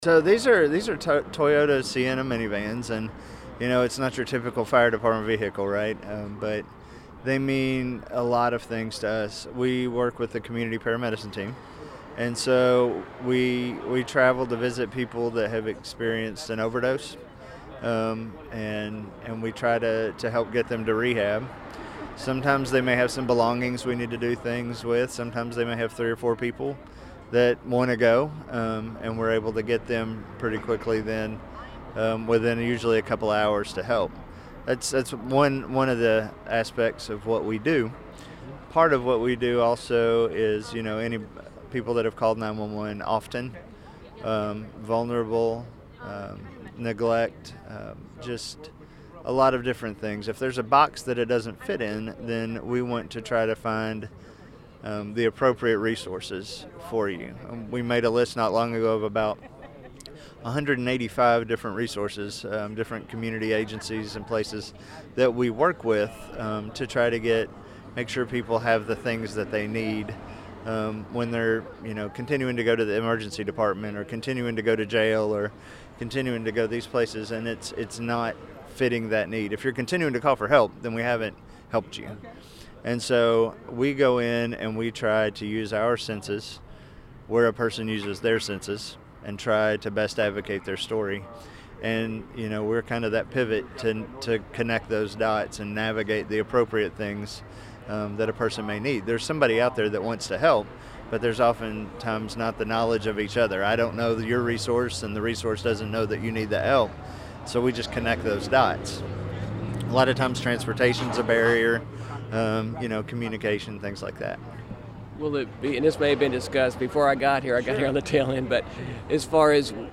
Here's the entire interview